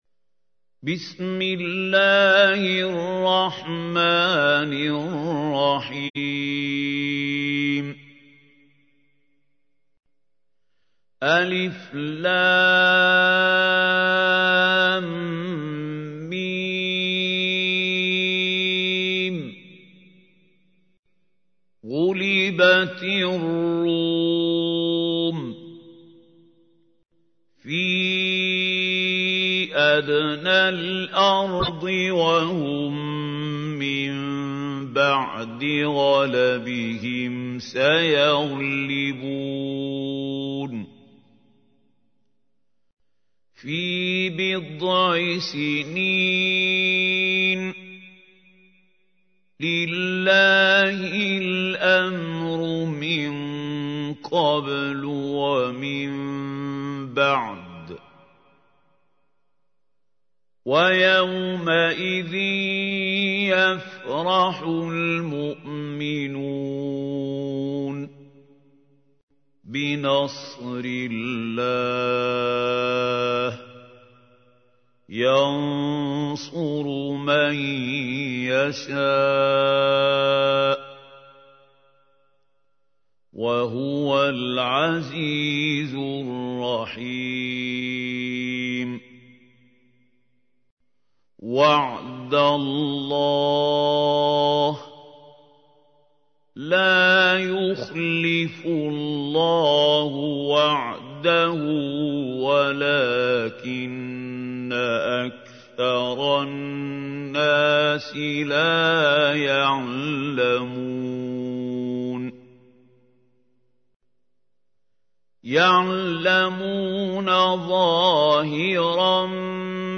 تحميل : 30. سورة الروم / القارئ محمود خليل الحصري / القرآن الكريم / موقع يا حسين